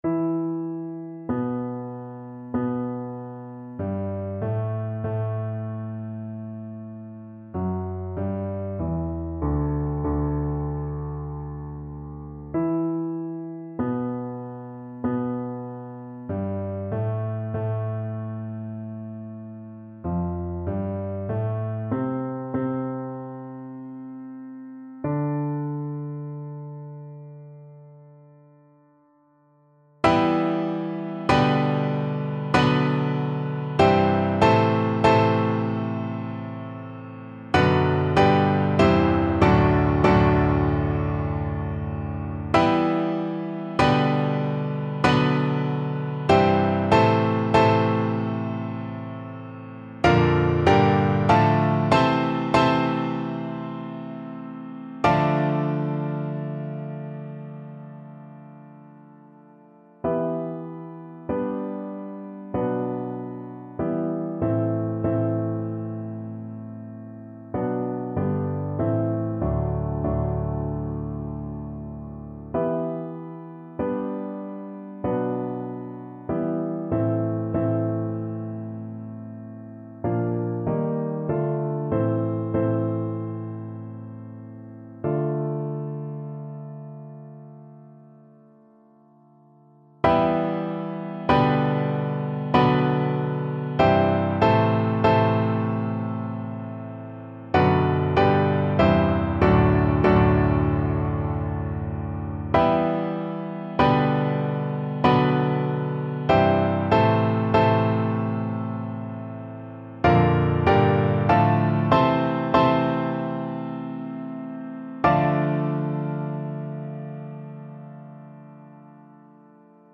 Satie, Erik - 4 Ogives Free Sheet music for Piano
Instrument: Piano
Style: Classical